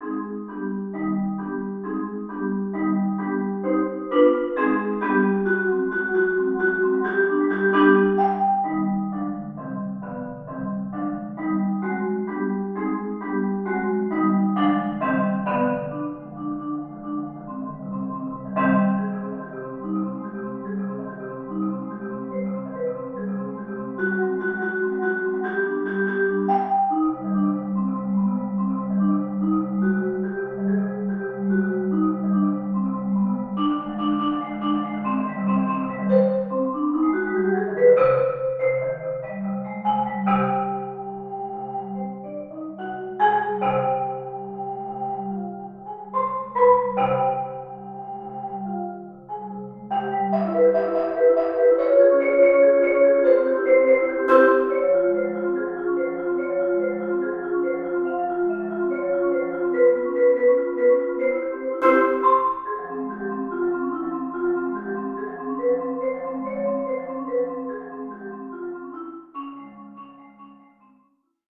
Genre: Marimba (4-mallet)
Using the Greek modes with which he is so familiar
Marimba (4.5-octave)